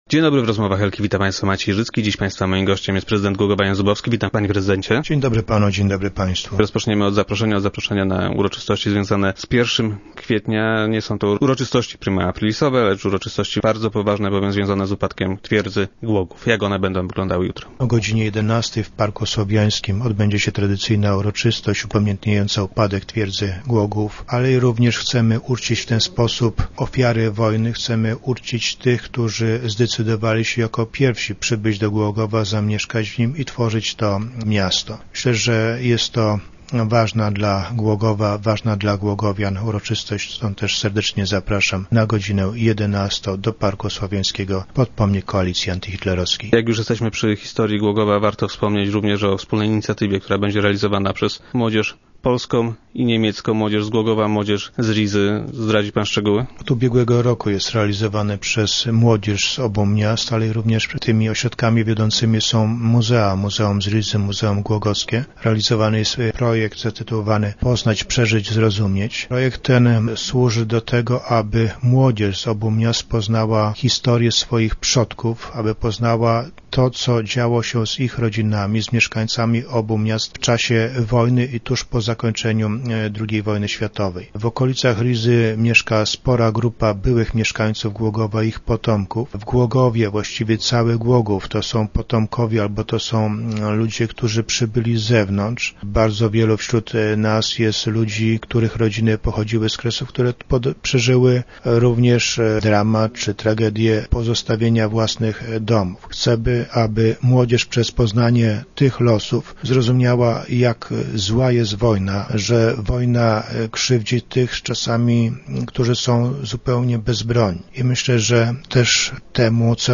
Zrozumieć" - Chcemy by młodzież z obu miast poznała historię swych przodków - powiedział prezydent Jan Zubowski, który był dziś gościem Rozmów Elki.